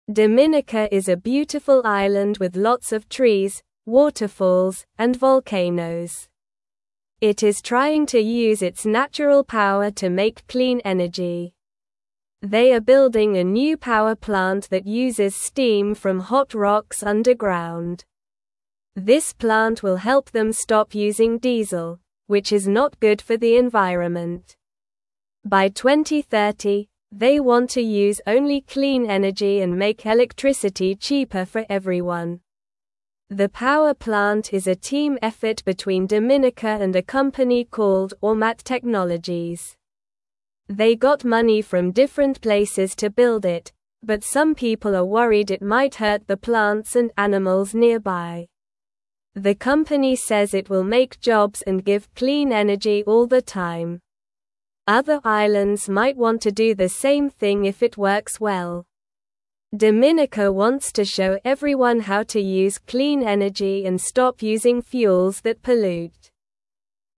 Slow
English-Newsroom-Beginner-SLOW-Reading-Dominicas-Clean-Energy-Plan-for-a-Bright-Future.mp3